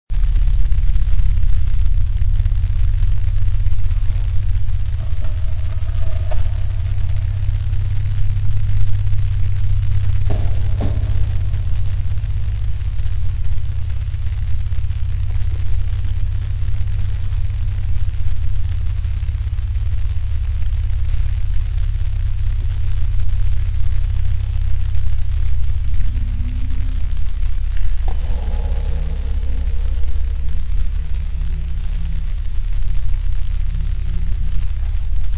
Noisepack " 噪声低的隆隆声
描述：各种类型和形式的audionoise集合的一部分（有待扩大）。
Tag: 配音 毛刺 噪声 噪声 配音 柔软